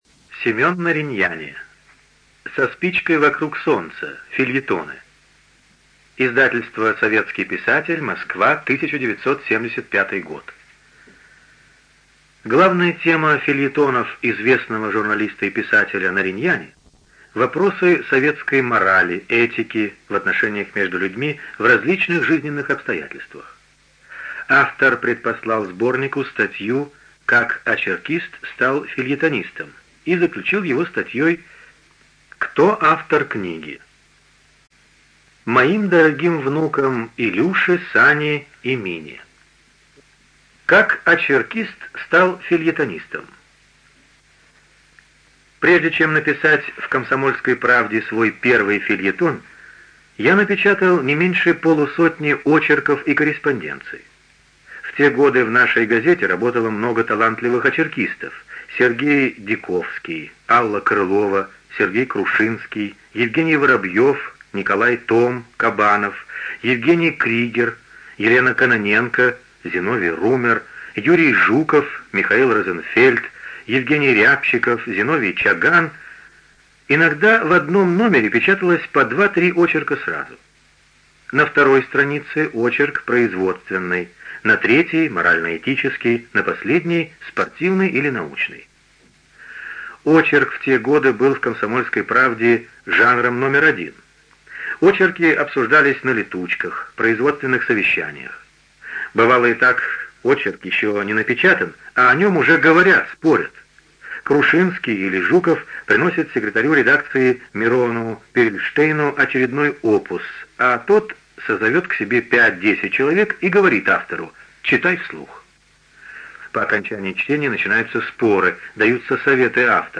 ЖанрЮмор и сатира
Студия звукозаписиЛогосвос